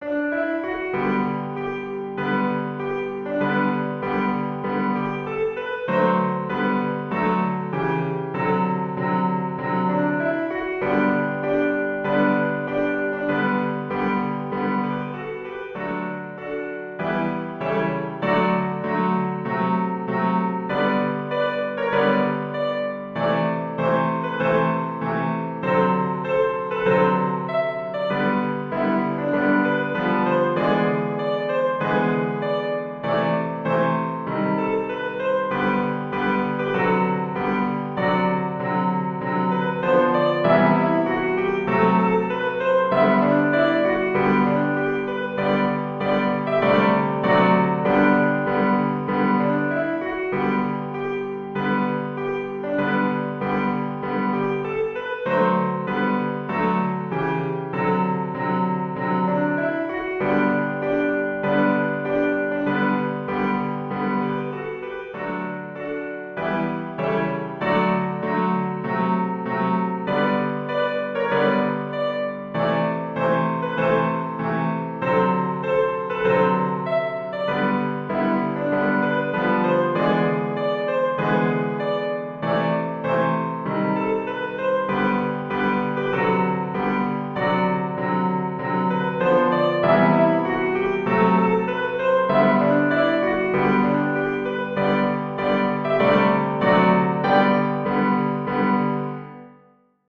Tradizionale Genere: Religiose